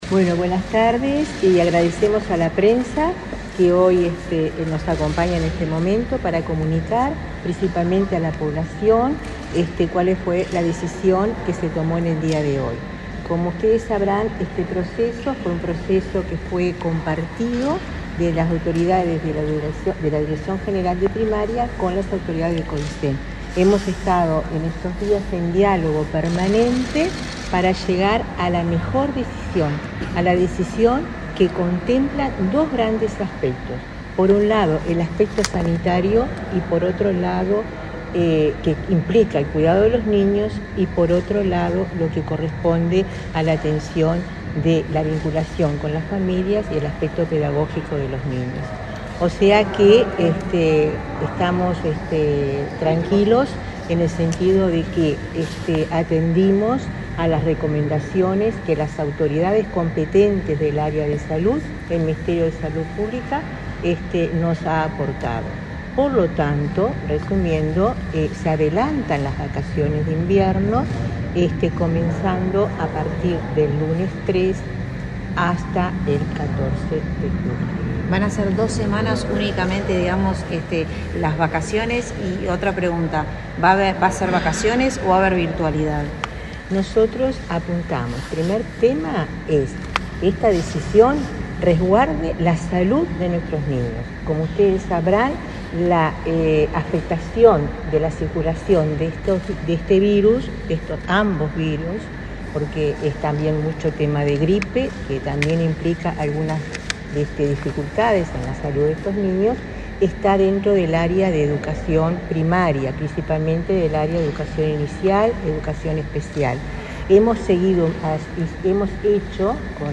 Conferencia de prensa de la directora general de Educación Inicial y Primaria, Olga de las Heras